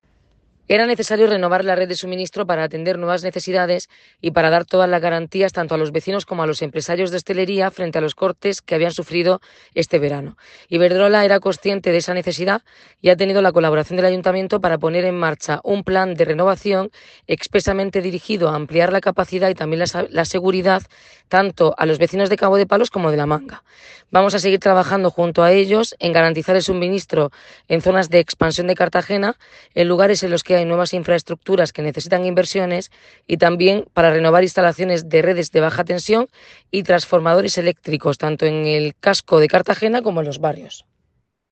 Enlace a Declaraciones de la alcaldesa Noelia Arroyo. aciones